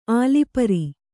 ♪ ālipari